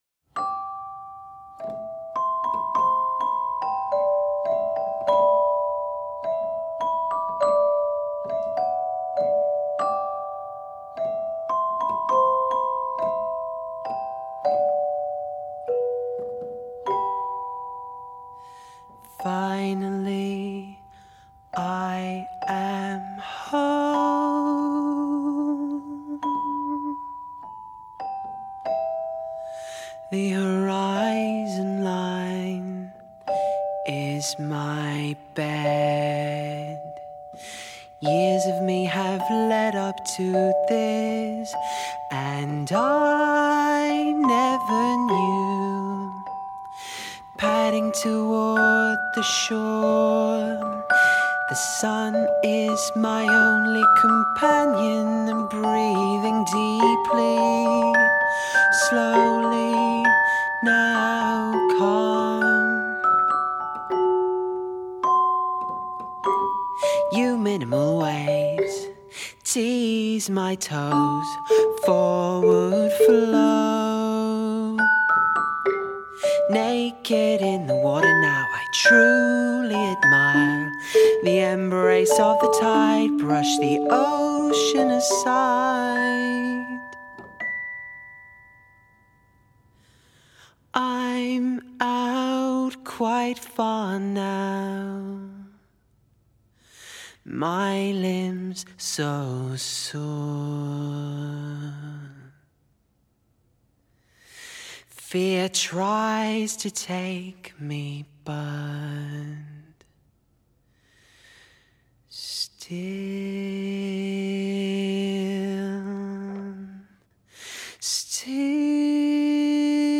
クラシカルなチェンバー・ポップ！